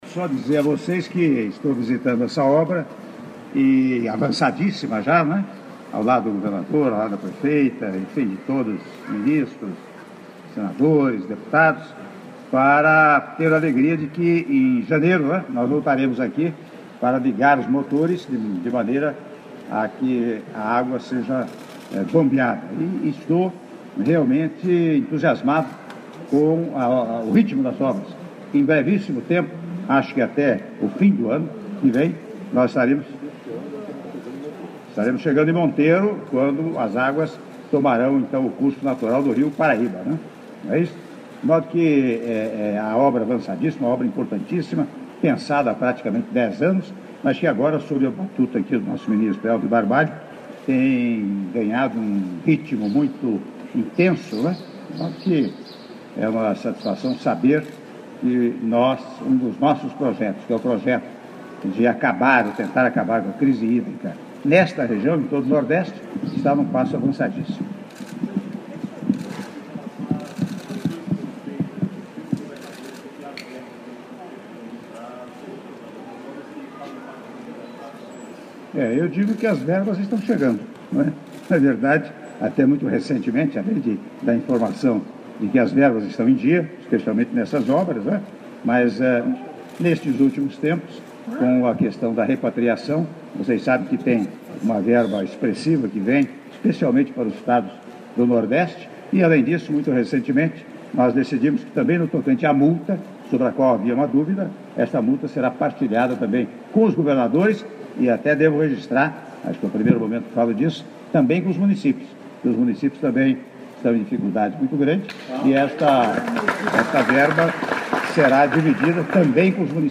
Áudio da entrevista do presidente da República, Michel Temer, após visita à Estação de Bombeamento EBV-3 - Reservatório Salgueiro- Floresta/PE (02min50s)